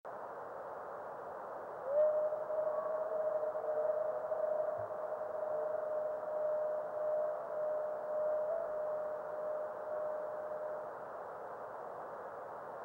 video and stereo sound:
Radio spectrogram of the time of the above meteor.  61.250 MHz reception above white line, 83.250 MHz below white line.